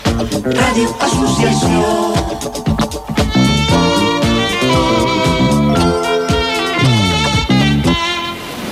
Indicatiu de l'emissora
Banda FM